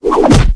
bash2.ogg